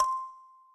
kalimba_c1.ogg